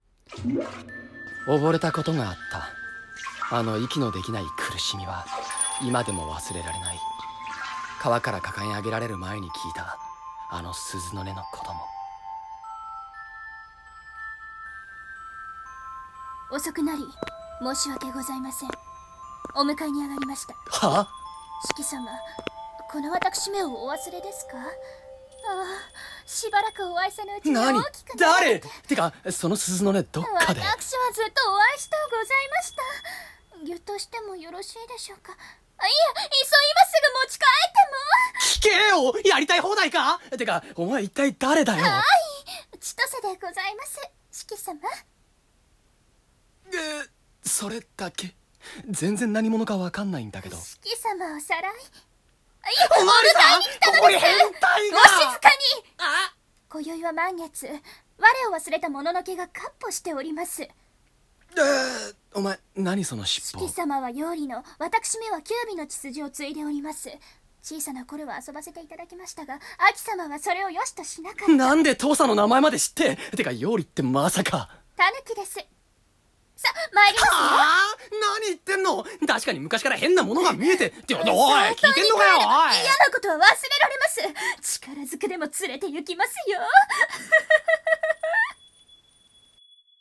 掛け合い台本『妖狐は月夜に鈴を鳴らす』